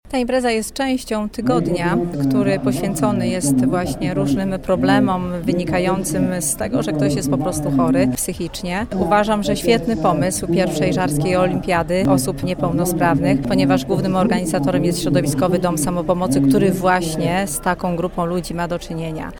Danuta Madej, burmistrz Żar podkreśla, że inicjatywa jest znakomita, bo osoby z różnego rodzaju schorzeniami mają takie same potrzeby, jak osoby zdrowe.